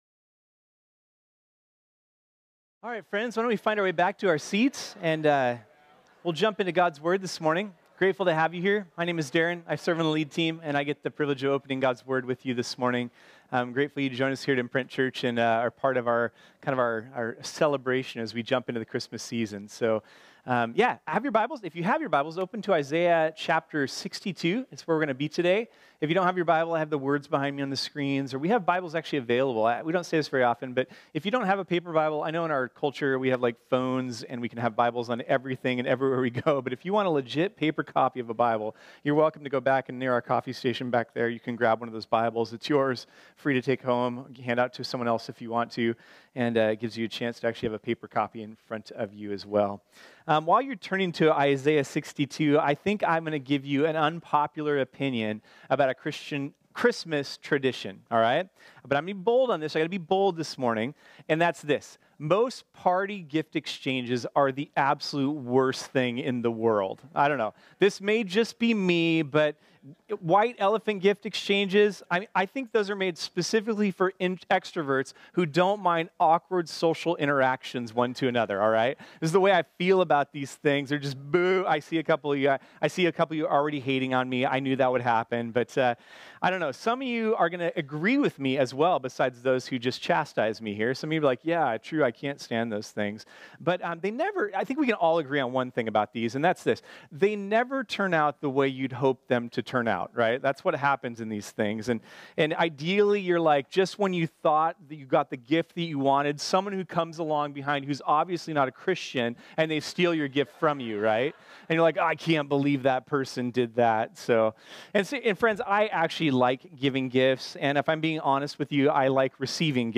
This sermon was originally preached on Sunday, December 16, 2018.